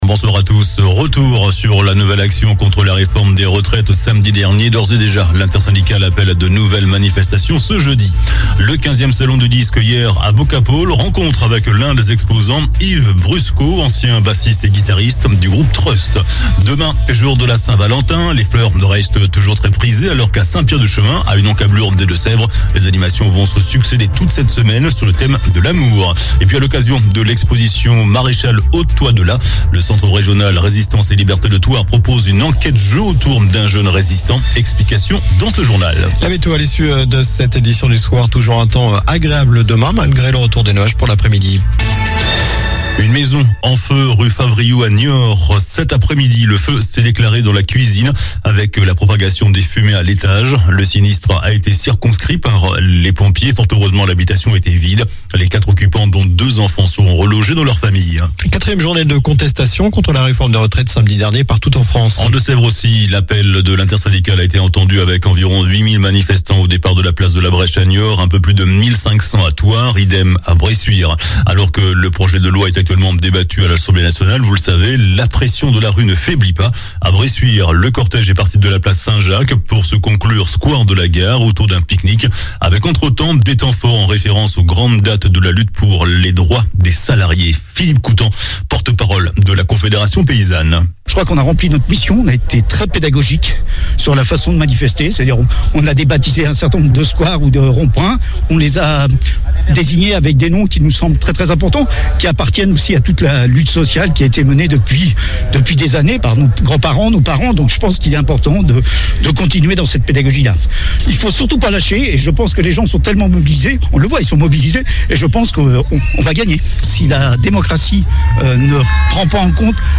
JOURNAL DU LUNDI 13 FEVRIER ( SOIR )